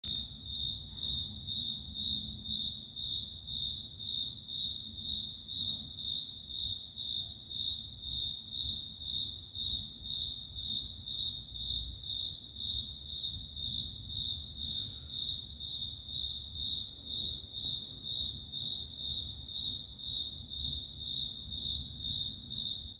Field Cricket (Gryllus sp.)
Sex: Male
Province / Department: Ciudad Autónoma de Buenos Aires
Location or protected area: Belgrano
Condition: Wild